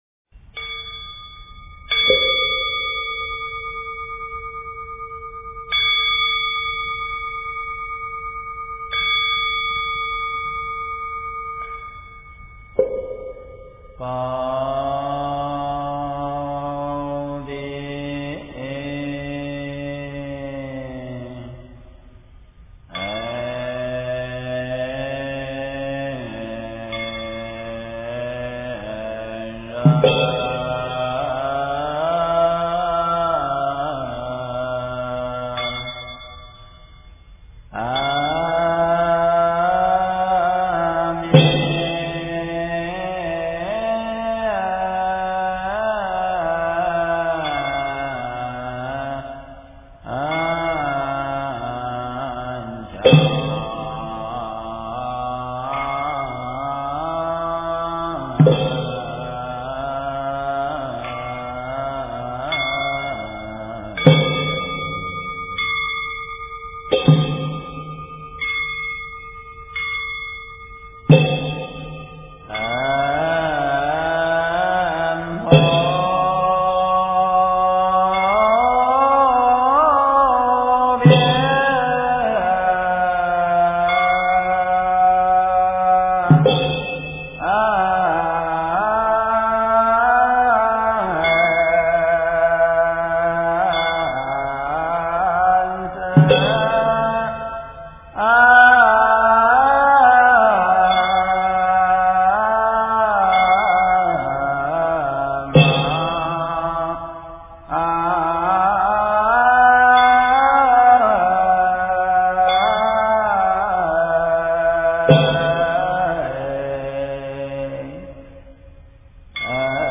标签: 佛音经忏佛教音乐